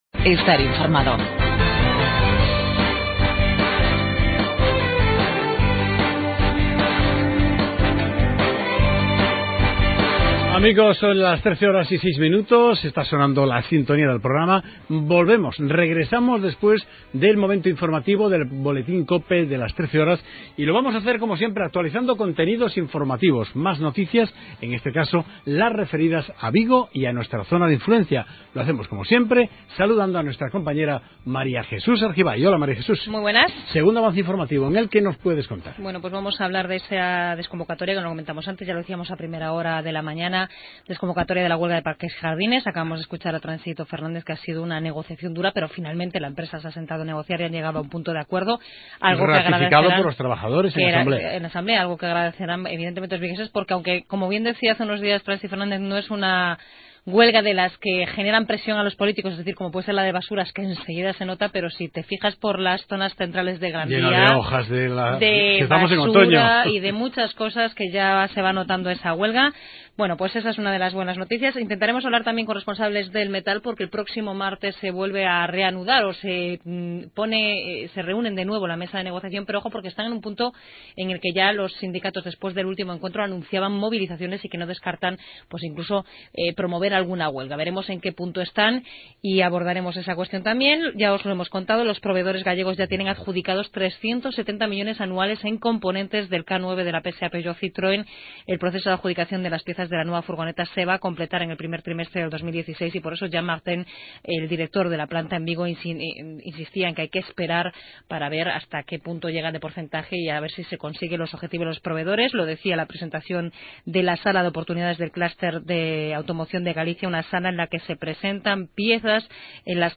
Entrevista a: